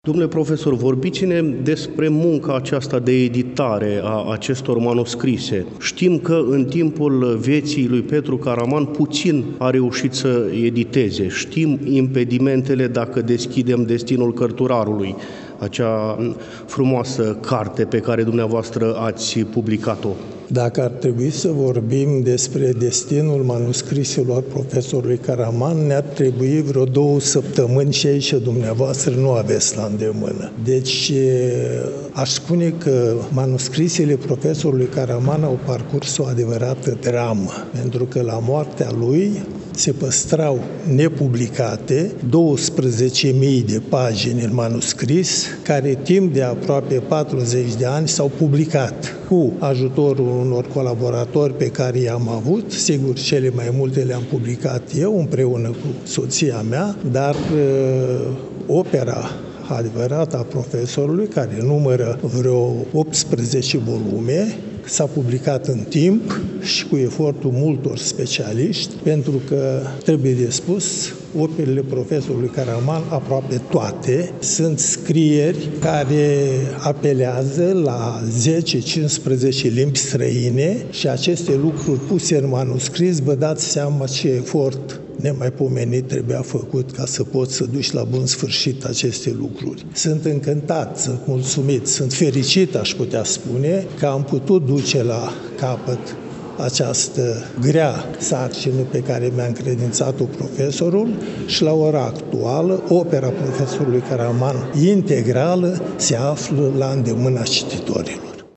Cu prilejul împlinirii, la 14 decembrie 2023, a 125 de ani de la nașterea cel mai mare etnolog român, la Iași, în Sala „Hasdeu” din incinta BCU „Mihai Eminescu”, a fost organizat un eveniment de înaltă ținută academică.